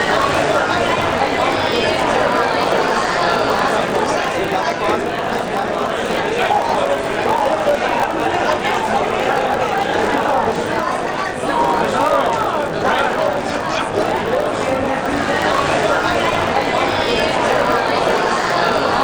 Party_crowd_1.ogg